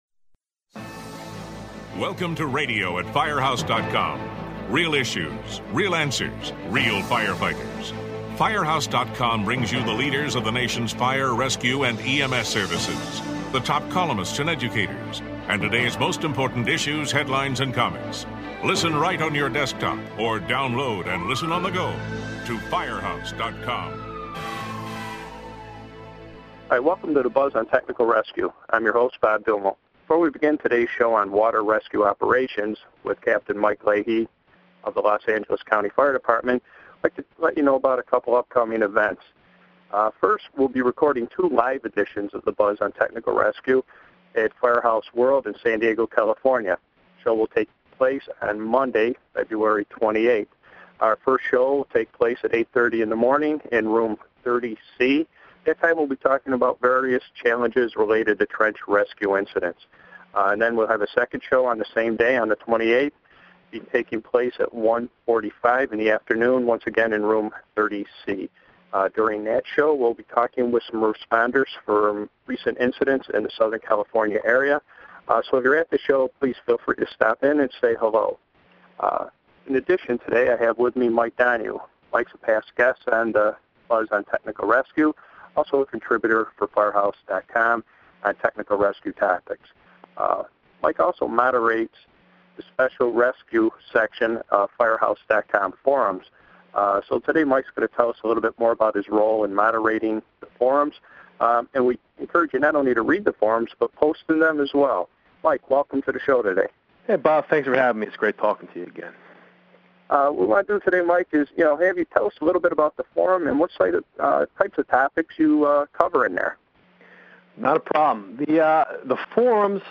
The two discuss land, water and air based responses and training and with an emphasis on personnel safety and the right equipment.